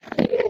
Sound / Minecraft / mob / endermen / idle2.ogg